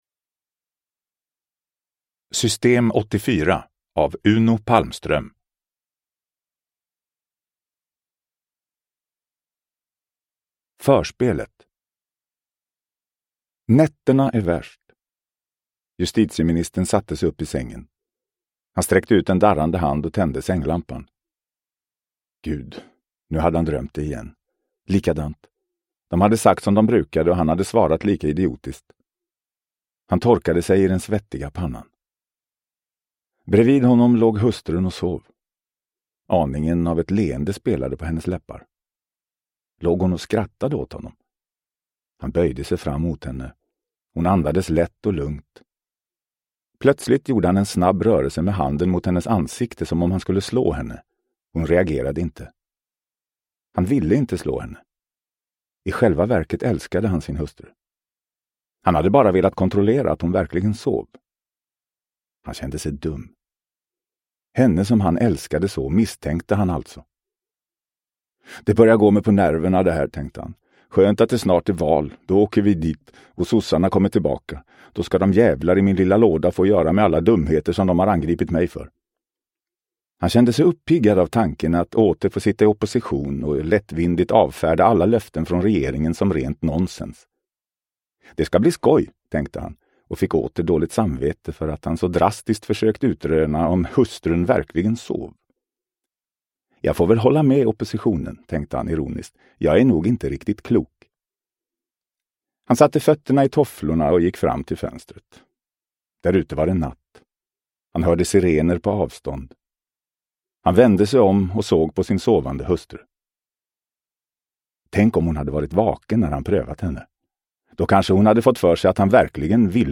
System 84 – Ljudbok – Laddas ner